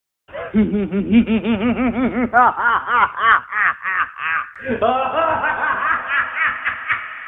Звуки со смехом злого ученого, маньяка и его лаборатория для монтажа видео в mp3 формате.
7. Гениальный ученый хохочет